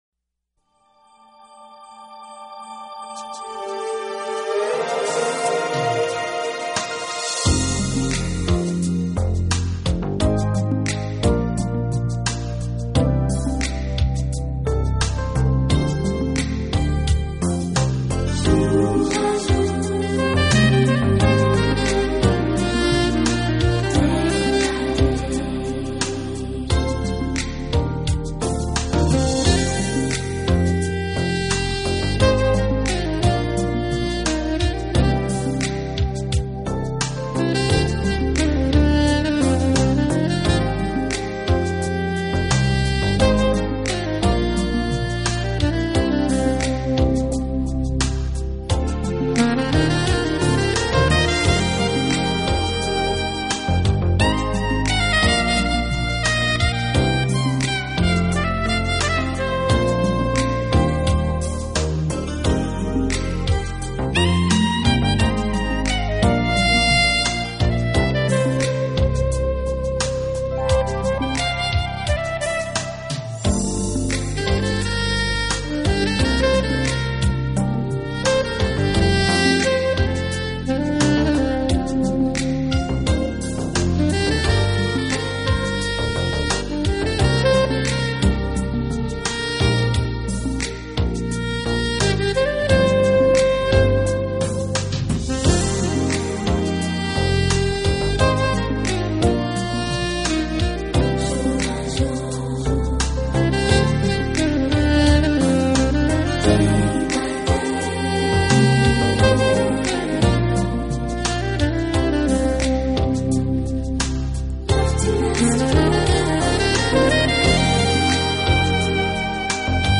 是一名高音萨克斯风的演奏好手
的演奏给人的感觉是深沉而平静，轻柔而忧伤，奇妙而富有感情。